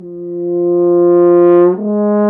Index of /90_sSampleCDs/Roland L-CD702/VOL-2/BRS_F.Horn FX/BRS_Intervals
BRS F HRN 04.wav